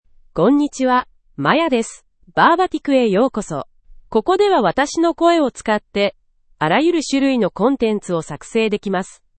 Maya — Female Japanese (Japan) AI Voice | TTS, Voice Cloning & Video | Verbatik AI
Maya is a female AI voice for Japanese (Japan).
Voice sample
Listen to Maya's female Japanese voice.
Maya delivers clear pronunciation with authentic Japan Japanese intonation, making your content sound professionally produced.